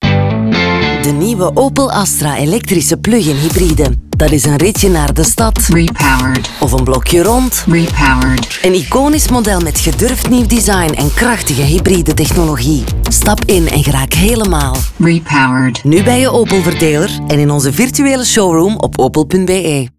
Radio Production: Sonhouse